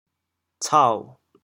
“操”字用潮州话怎么说？
cao3.mp3